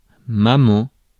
Ääntäminen
France: IPA: [ma.mɑ̃]